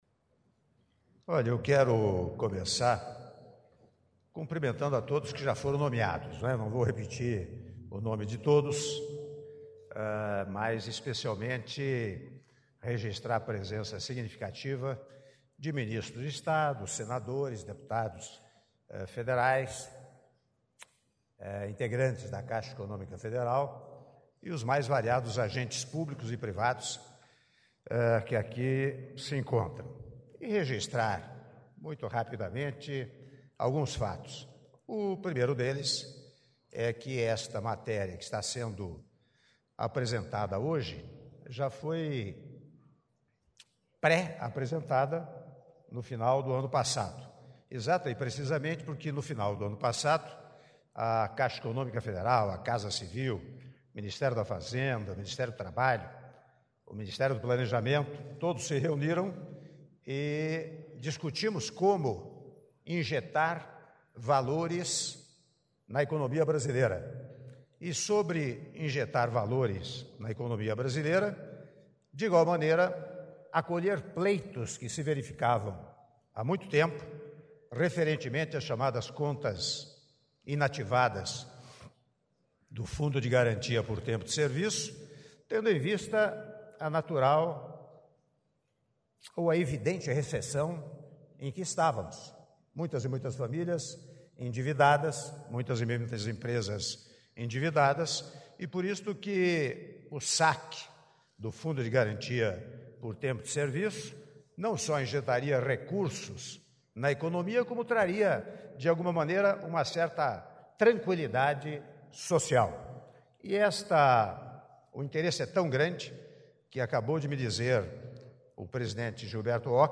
Áudio do discurso do Presidente da República, Michel Temer, durante cerimônia de Anúncio do Calendário de Saque de Contas Inativas do FGTS - Palácio do Planalto (08min13s)